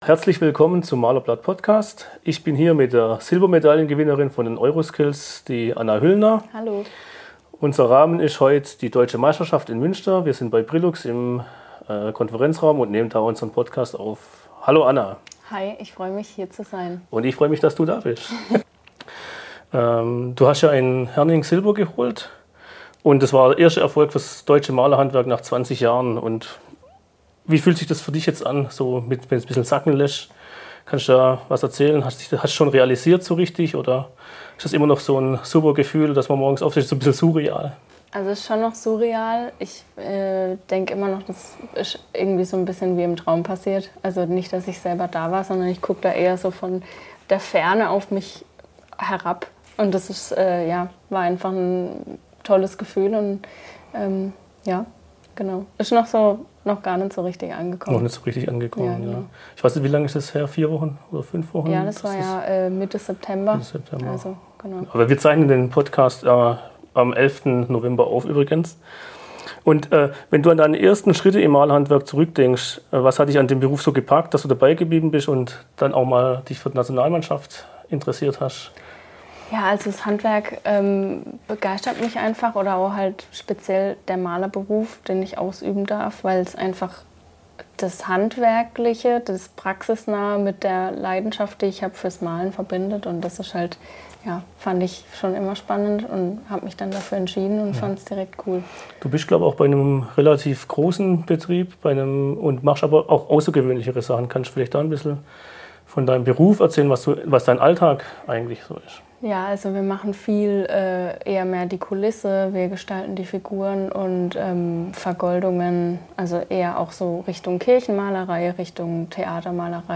Das Gespräch wurde bei den Deutschen Meisterschaften im November aufgenommen und gibt einen persönlichen Einblick in Spitzenleistung im Malerhandwerk.